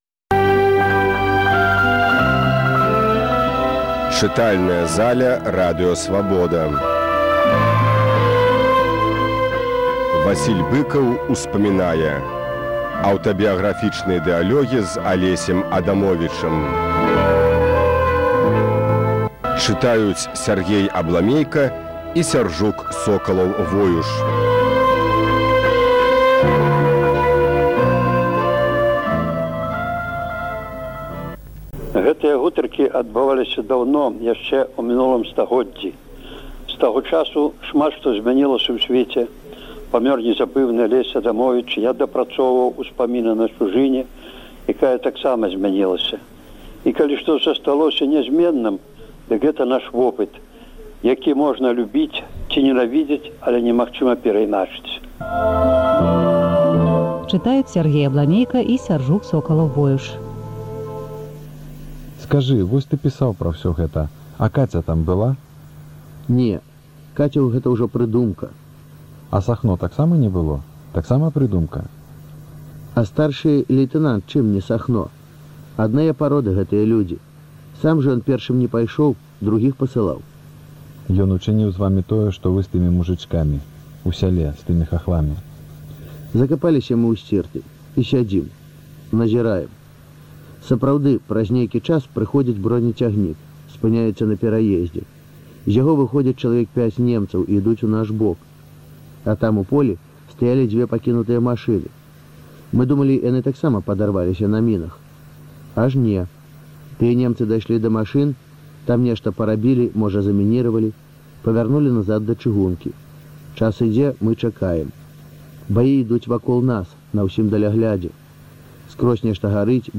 Архіўны запіс перадачы, якая ўпершыню прагучала на хвалях Свабоды ў 2001 годзе.